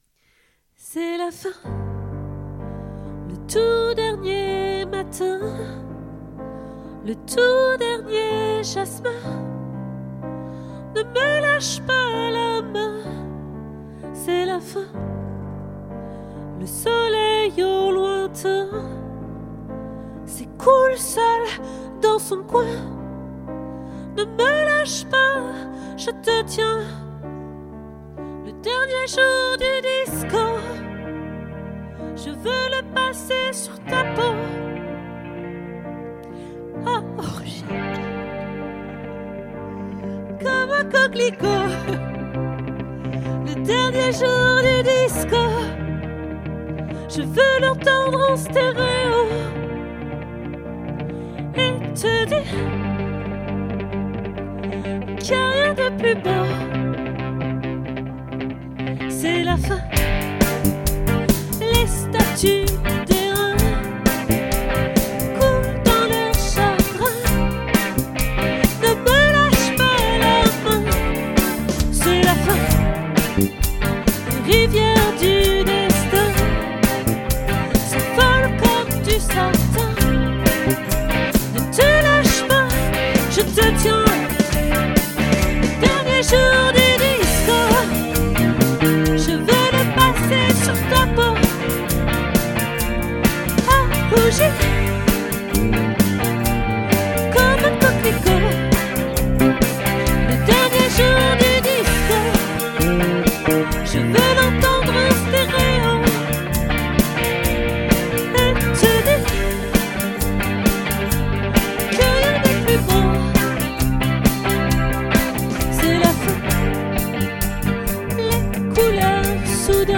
🏠 Accueil Repetitions Records_2024_01_29